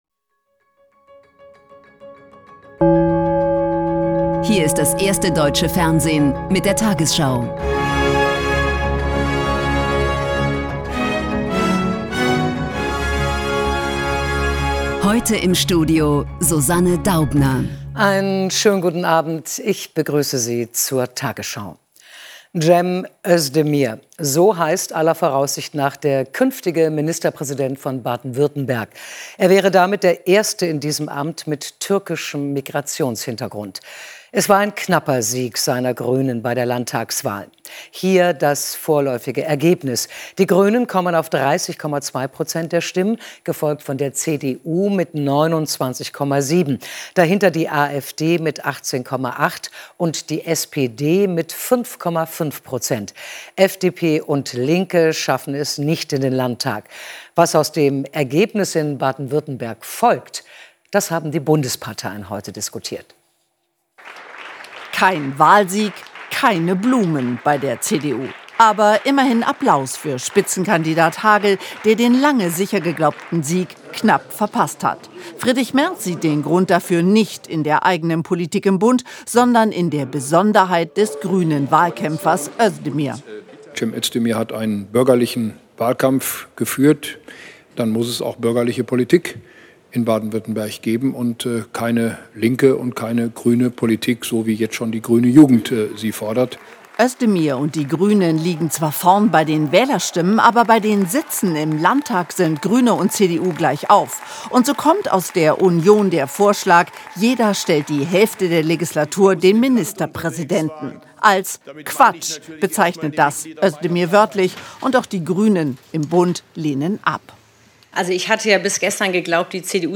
Die 20 Uhr Nachrichten